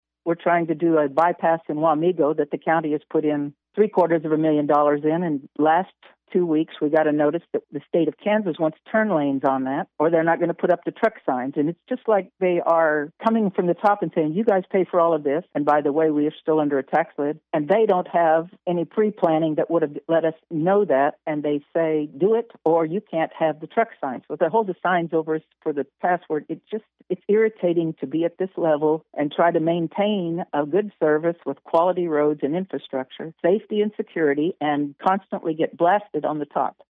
Dee McKee, a Pottawatomie County commissioner, spoke about some of her concerns on Tuesday’s In Focus, saying being part of the FHMPO makes gaining access to federal funds more complicated.